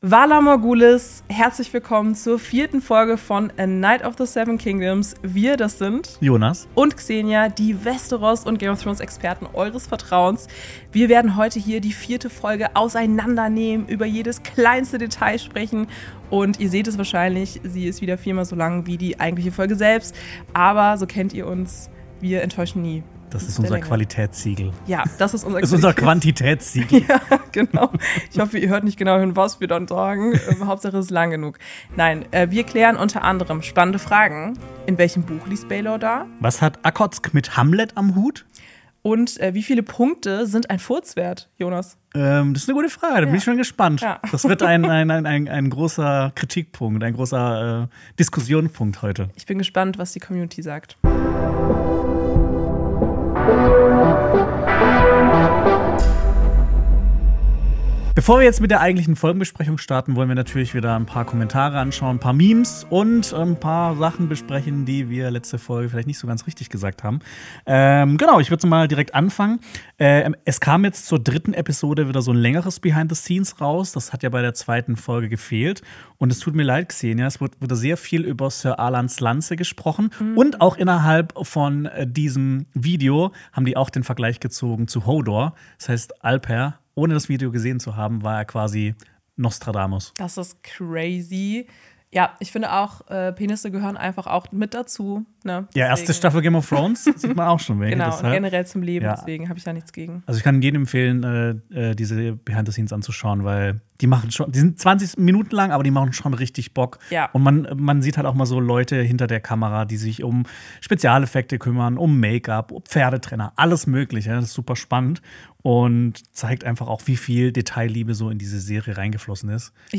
Beschreibung vor 2 Monaten Neue Woche, neue Folgenbesprechung! In der vierten Folge von A KNIGHT OF THE SEVEN KINGDOMS steht Dunc vor der scheinbar unmöglichen Aufgabe, sechs weitere Ritter zu finden, um seine Unschuld in einem Urteil der Sieben zu beweisen.
Eine Diskussion über die Qualität der Folge darf natürlich auch nicht fehlen.